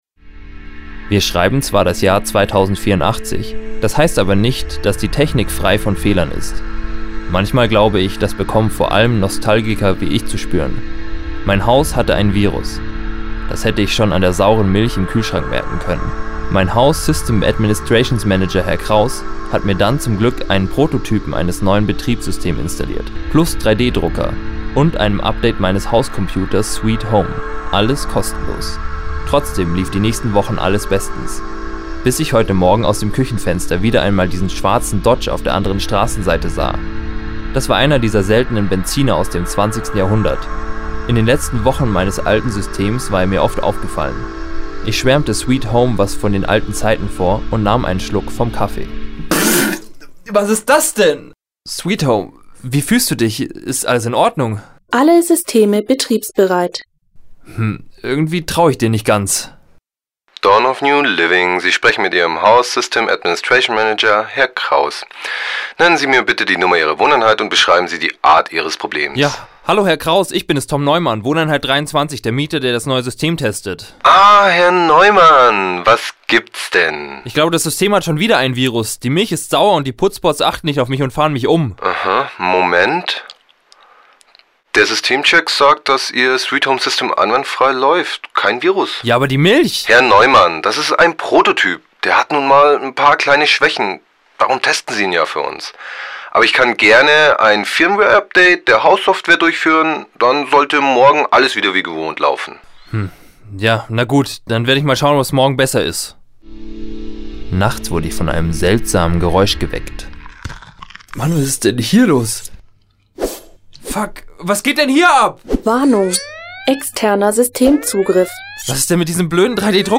15. Hörspiel